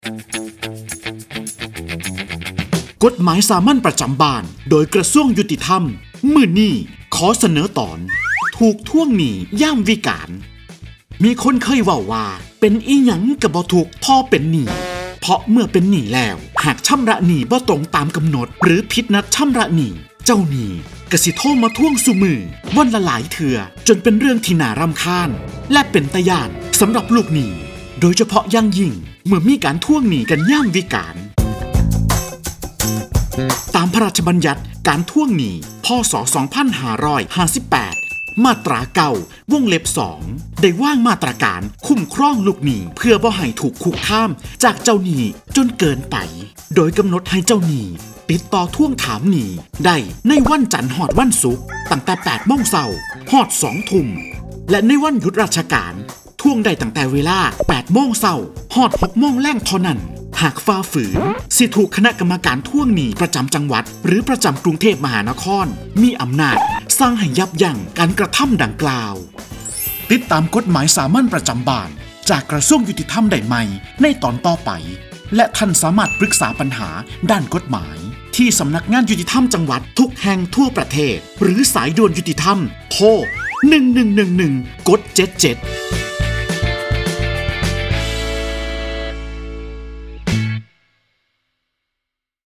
กฎหมายสามัญประจำบ้าน ฉบับภาษาท้องถิ่น ภาคอีสาน ตอนถูกทวงหนี้ยามวิกาล
ลักษณะของสื่อ :   คลิปเสียง, บรรยาย